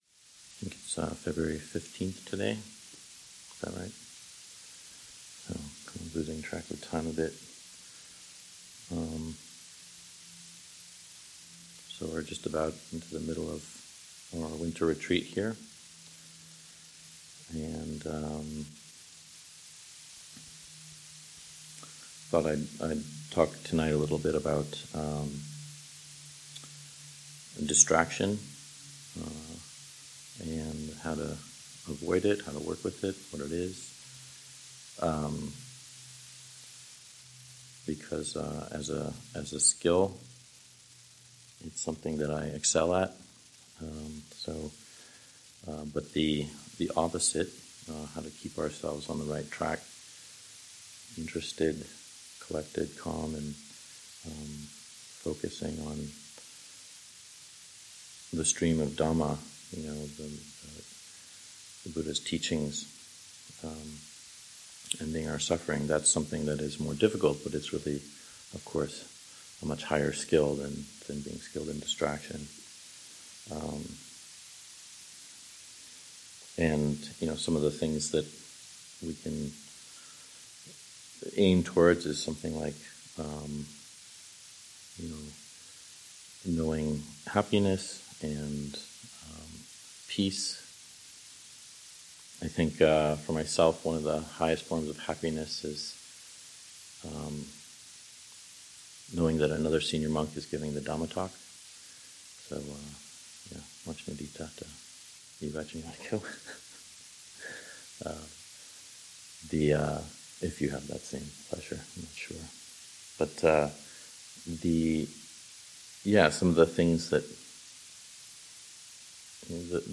Contemplating 4 Elements in the Body | Dhamma Talk at Dhammagiri 28:16